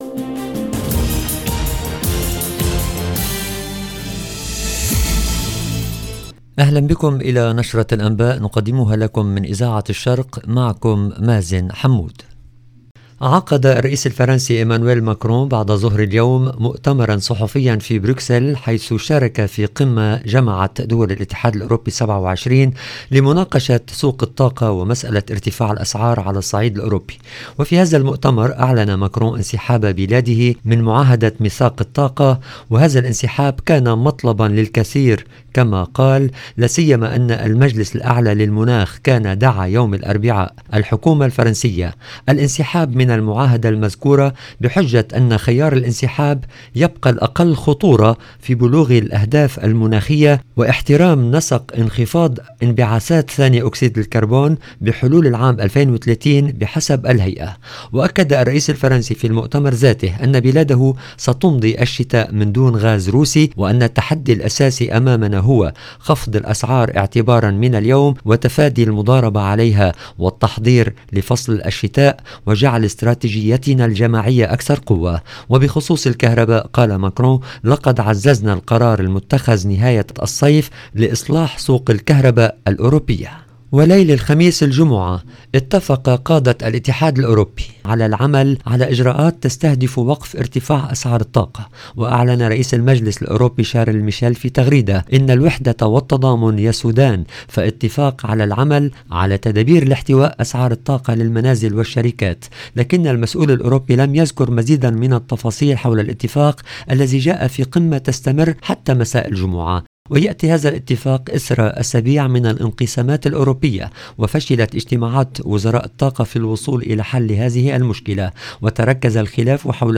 LE JOURNAL EN LANGUE ARABE DU SOIR DU 21/10/22